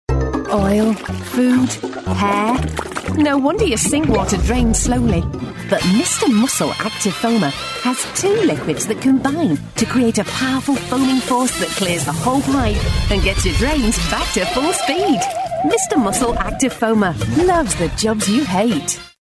Warm, Confident, Persuasive British-English Female Voice Over
Commercial Voice Over Samples
NORTHERN – National TV ad for Mr Muscle